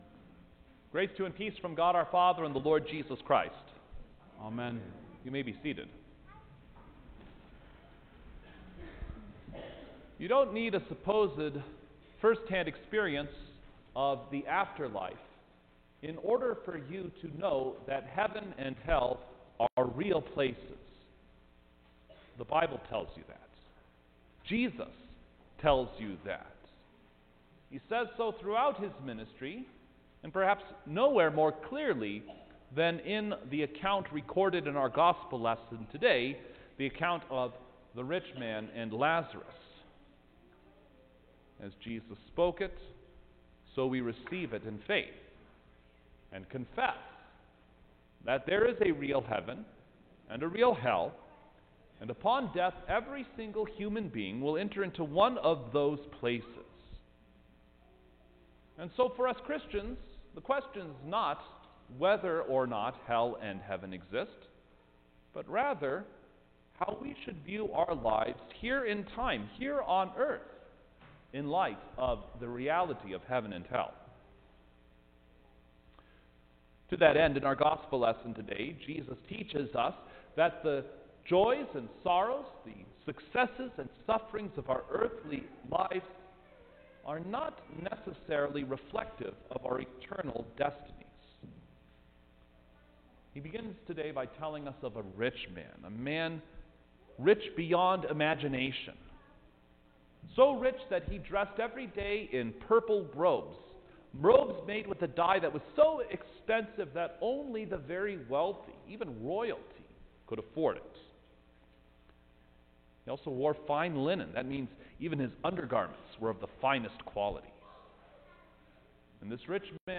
June-6_2021-First-Sunday-After-Trinity-Sermon-Stereo.mp3